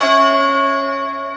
Key-bell_84.1.1.wav